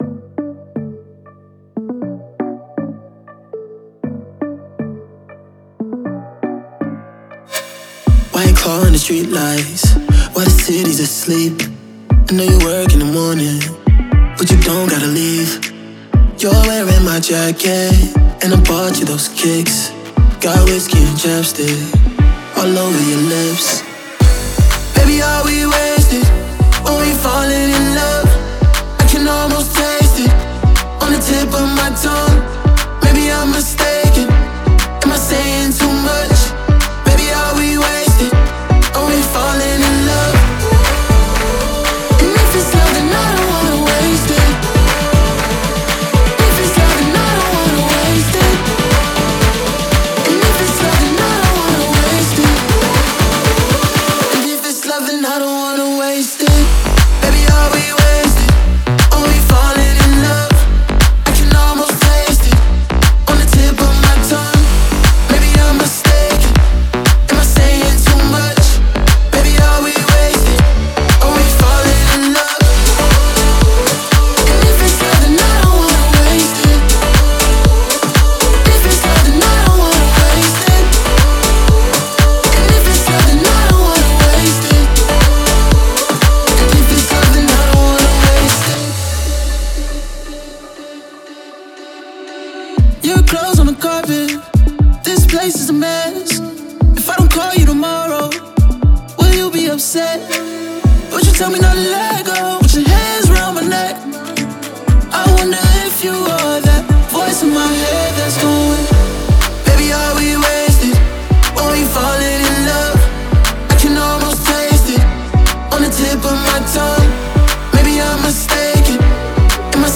энергичная электронная танцевальная композиция